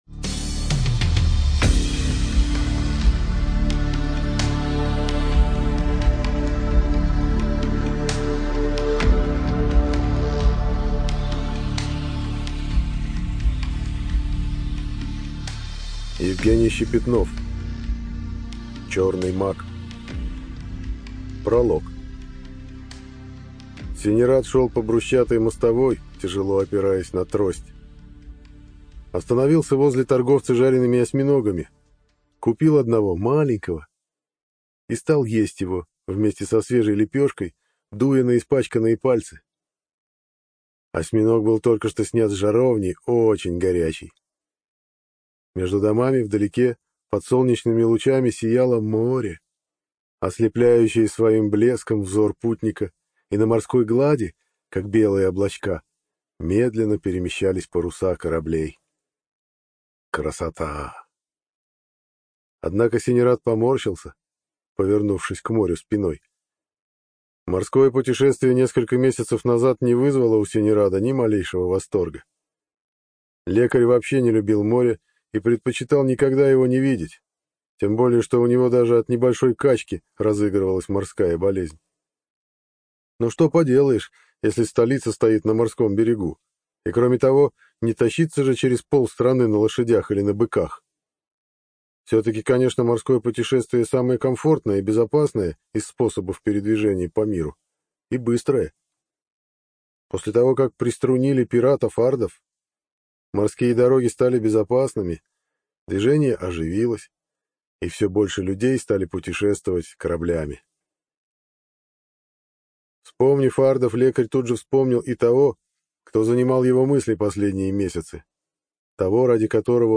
ЖанрБоевики, Фэнтези